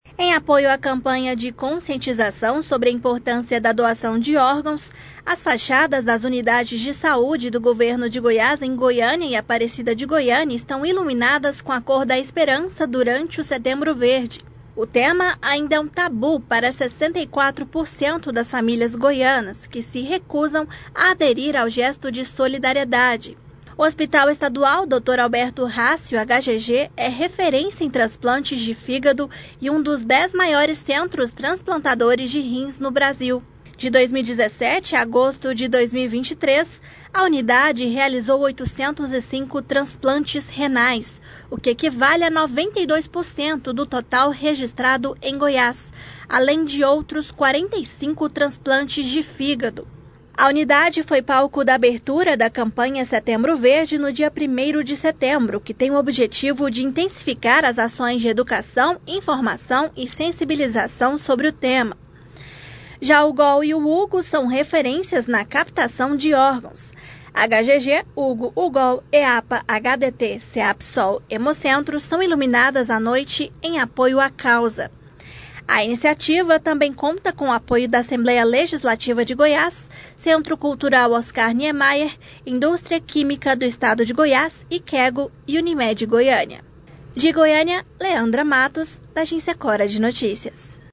Rádio